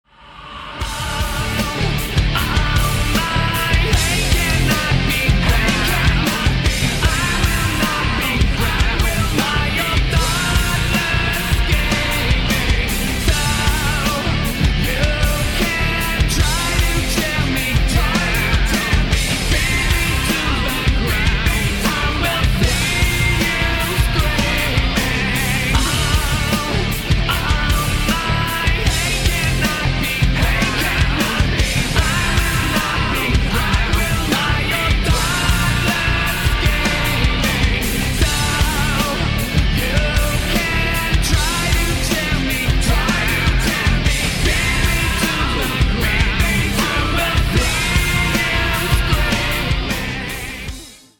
• Качество: 160, Stereo
гитара
мужской вокал
рок
ню-метал